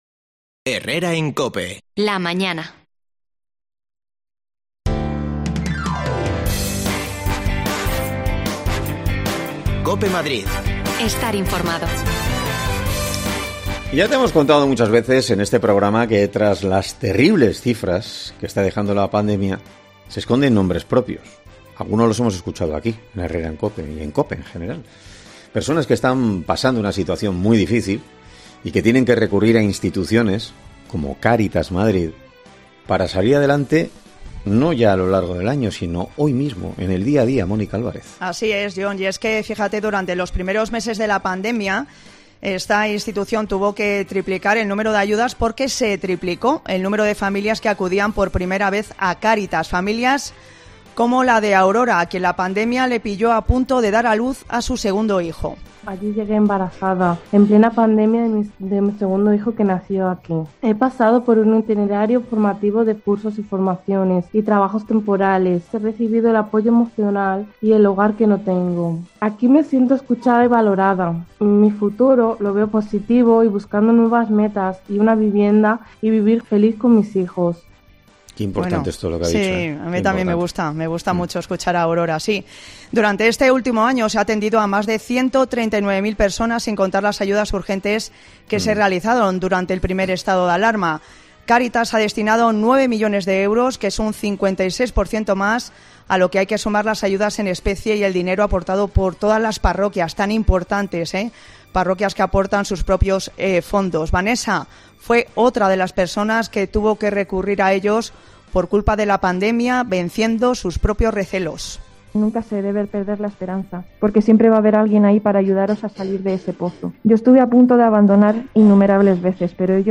AUDIO: Caritas Madrid ha atendido a más de 139 mil personas durante este último año marcado por la pandemia. Escuchamos a algunas de esas personas...
Las desconexiones locales de Madrid son espacios de 10 minutos de duración que se emiten en COPE , de lunes a viernes.